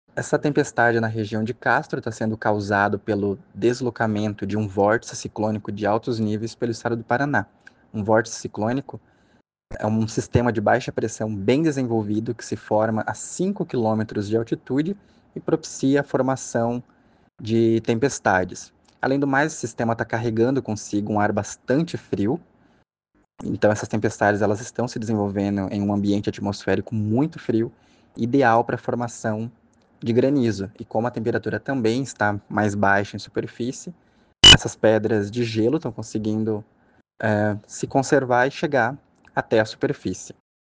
Sonora do meteorologista